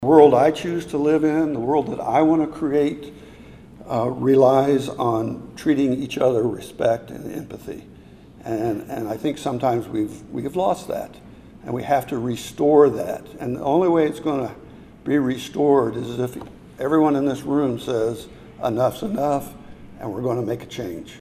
Leadership ideas were shared Wednesday in the annual 60 in 60 event, hosted by the Manhattan Mercury at the Manhattan Conference Center.
Former Manhattan State Rep. Tom Phillips says leadership comes from strong empathy.